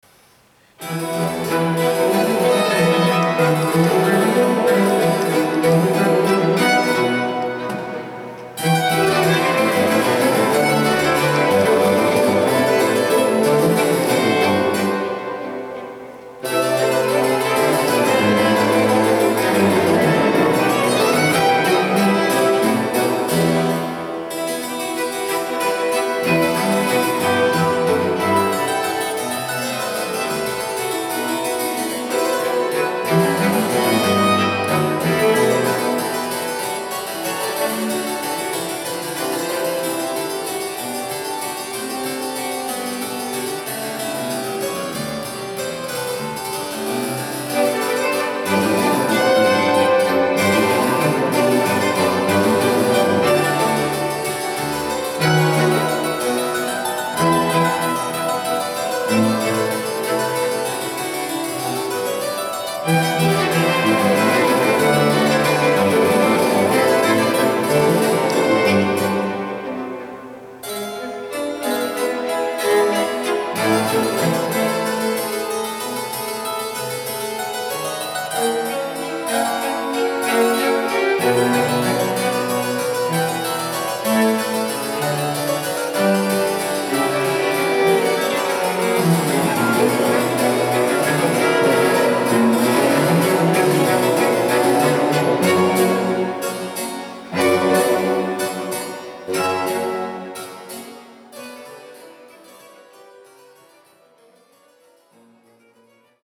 3.-J.S.-Bach-concert-voor-klavecimbel-strijkers-BWV-1056-in-f-kl-presto.mp3